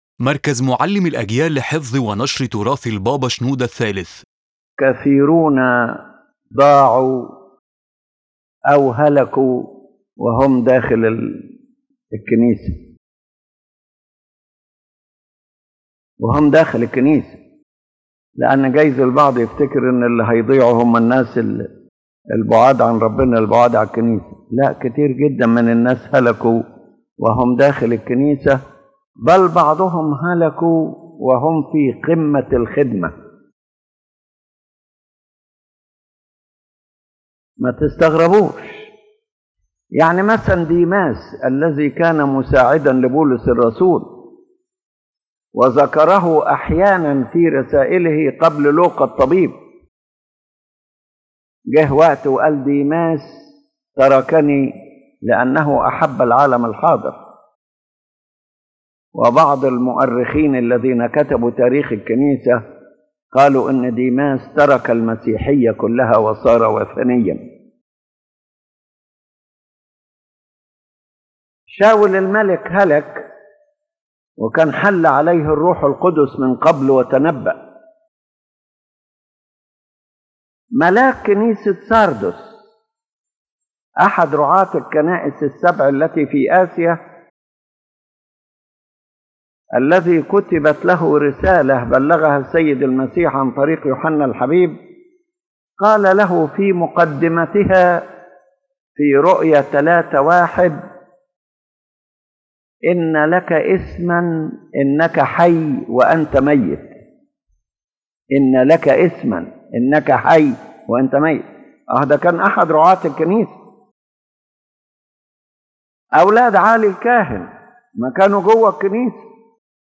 The lecture warns of a deep spiritual danger: that a person may be lost and perish while being inside the Church, and sometimes even at the height of ministry, if humility is lost and focus shifts to appearance, authority, or knowledge without personal salvation.